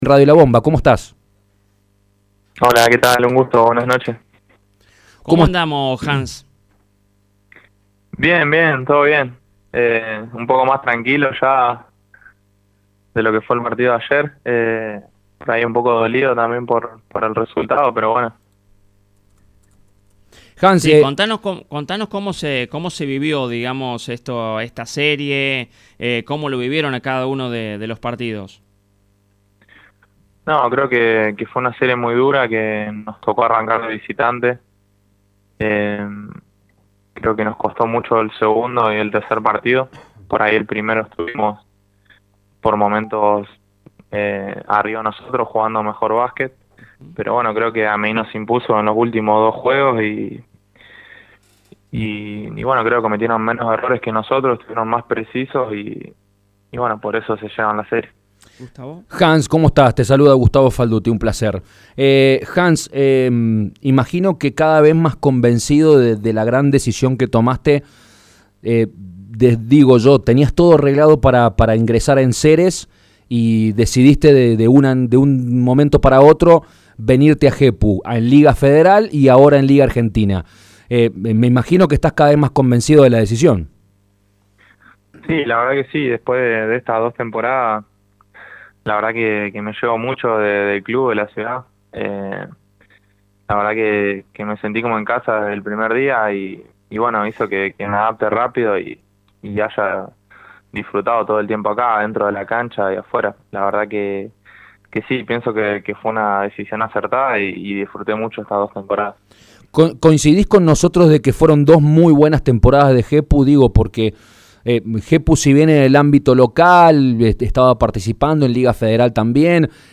en diálogo con Show Deportivo en Radio La Bomba